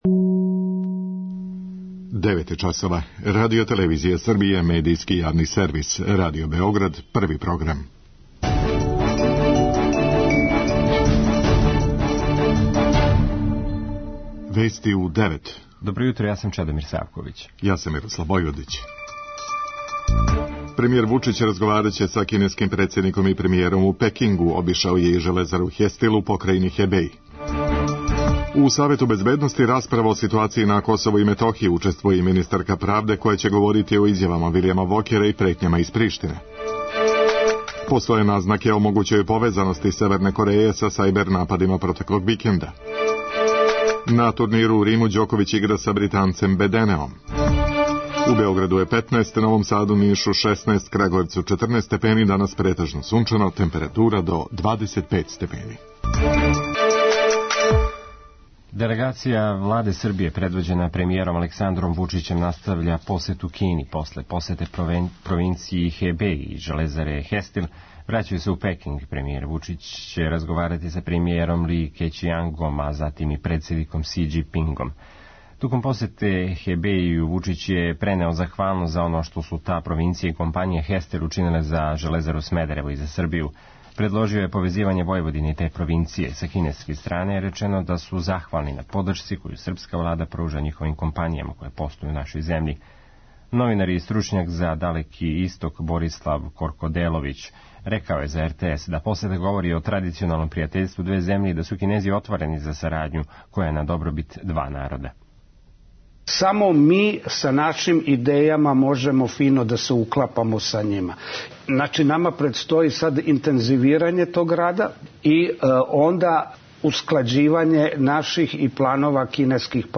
преузми : 3.39 MB Вести у 9 Autor: разни аутори Преглед најважнијиx информација из земље из света.